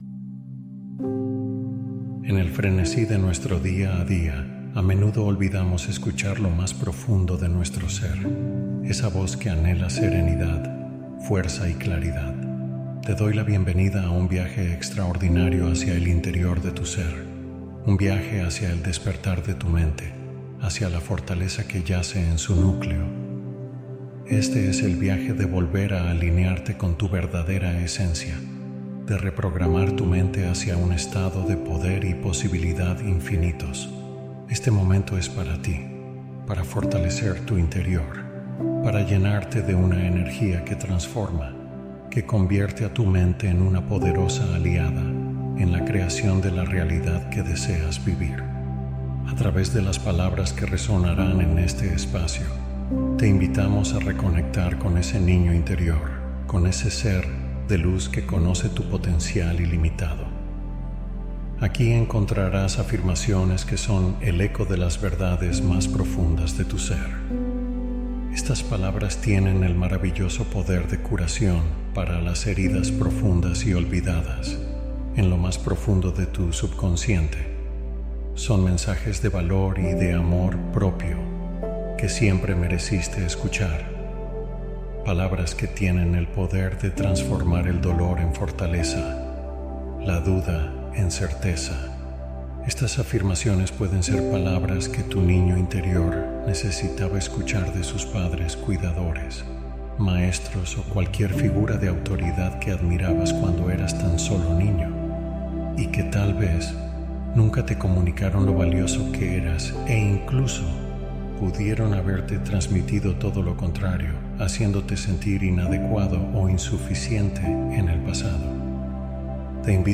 Sanación Cuántica Regresiva en Una Noche | Hipnosis Profunda